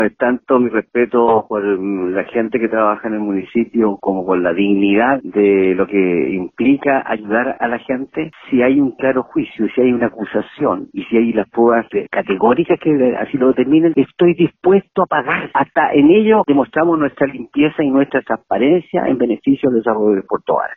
En conversación con La Radio, Ramón Bahamonde dijo que analiza querellarse en contra de la Contraloría.